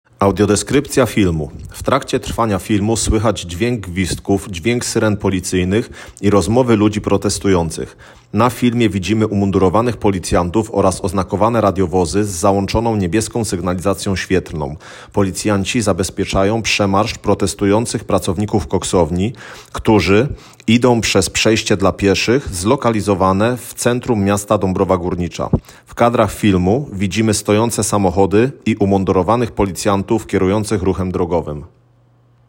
Nagranie audio Audiodeskrypcja_filmu-_protest_w_Centrum_DG.m4a